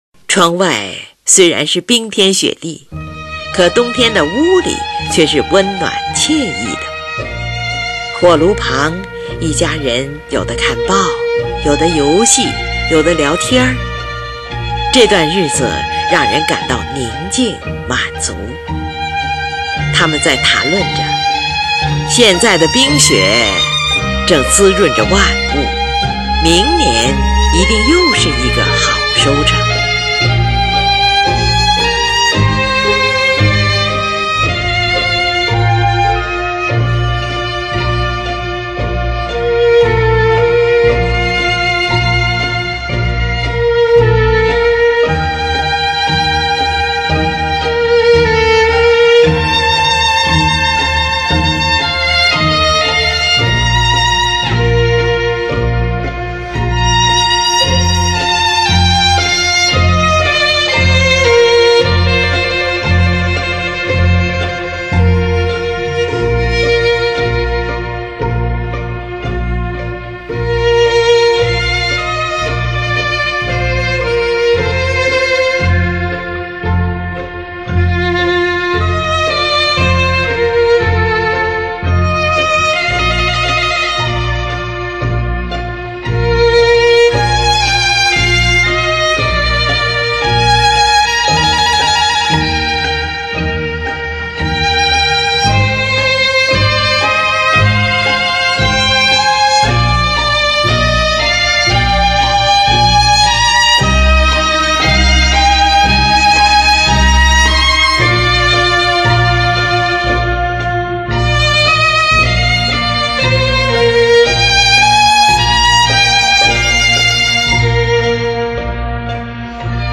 小提琴协奏曲
冬天--F小调
11. 广板，表现在火炉边过着宁静满足的岁月，屋外的雪水滋润着万物。